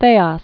(thāsŏs, thäsôs)